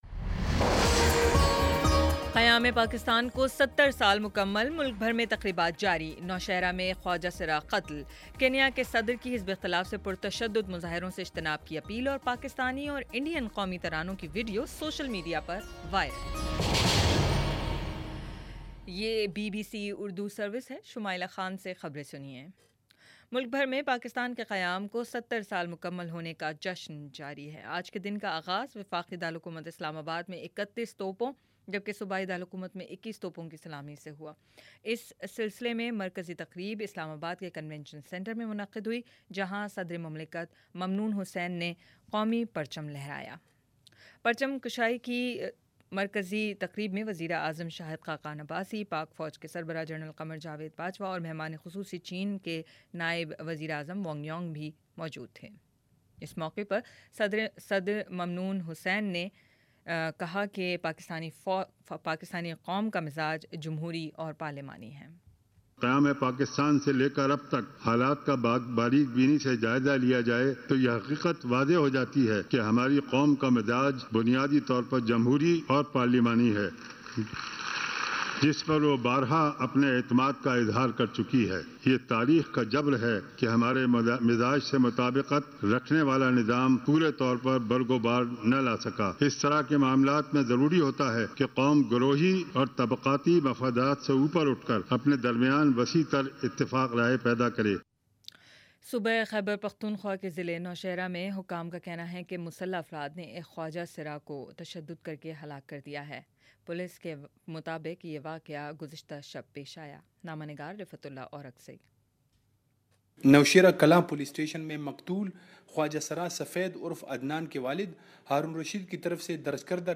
اگست 14 : شام چھ بجے کا نیوز بُلیٹن